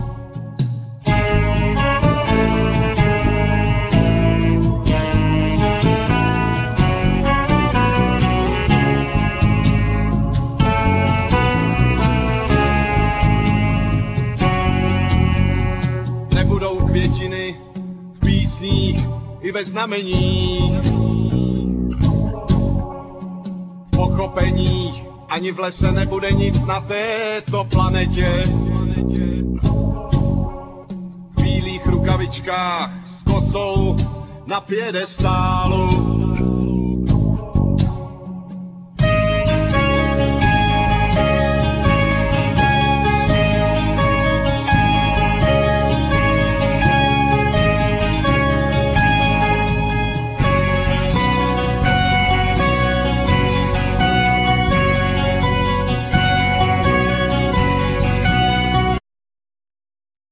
Vocal,Saxophne,Guitar solo,Synthsizer
Guitars,Synthsizer
Bass
Drums
Violin